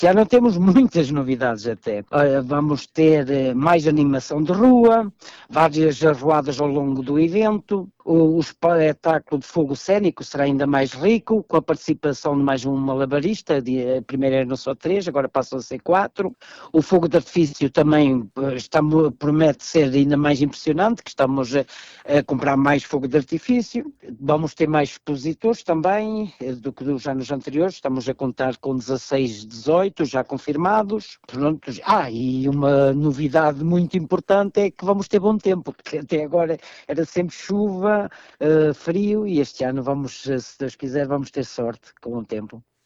A animação está garantida, com um programa cada vez mais diversificado, como refere o presidente da Junta de Freguesia de Vale de Prados, Ulisses Santos: